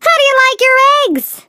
flea_ulti_vo_07.ogg